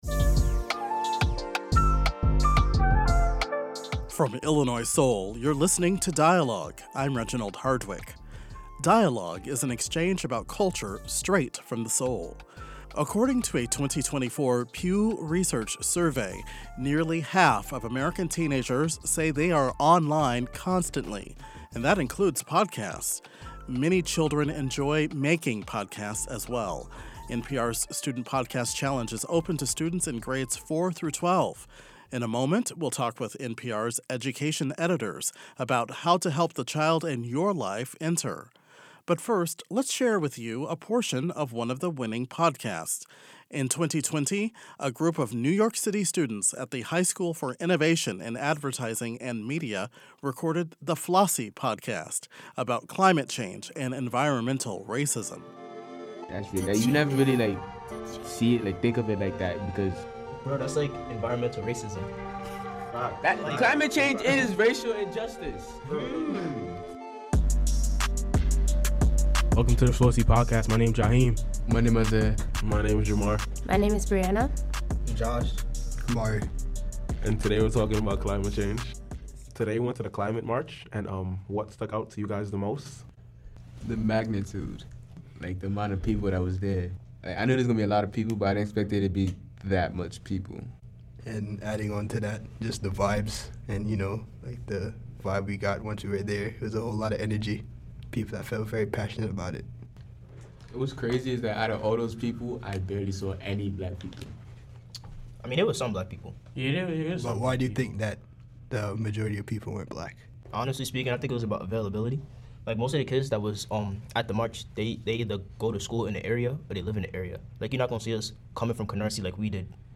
The Jackson water crisis through a student journalist's eyes 'Climate Change Is Racial Injustice': Students Speak Their Truth In Winning Podcast All past winners This interview has been edited for clarity and conciseness.